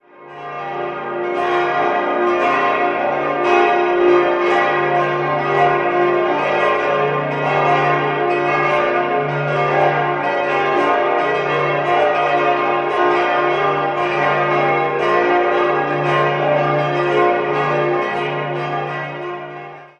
Idealsextett: d'-f'-g'-b'-c''-d'' Die Glocken 1 bis 3 wurden 1977 von der Firma Grassmayr in Innsbruck gegossen, die drei kleineren stammen aus dem Jahr 2008 und entstanden bei der ehemaligen Glockengießerei Perner in Passau.